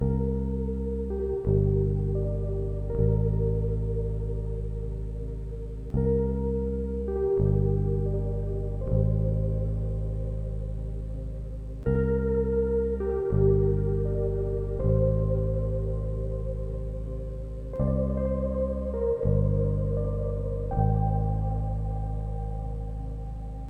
This is a 4 bar clip from a very long session. 1/2 figuring things out, 1/2 happily getting blown around wherever it wanted to go.